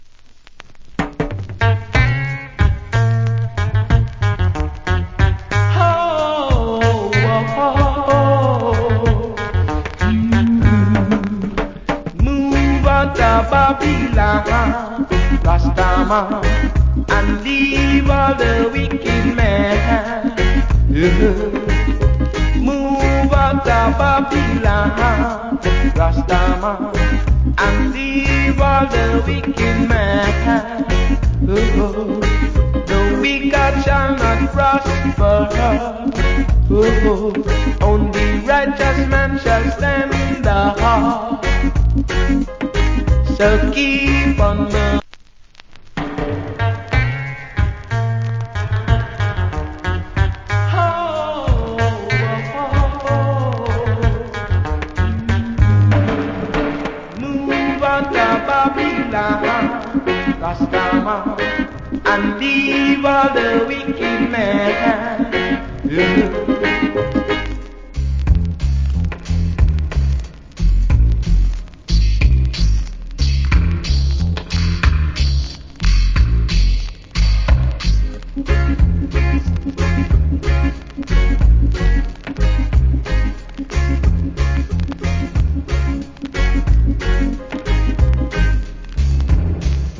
Roots Rock Vocal.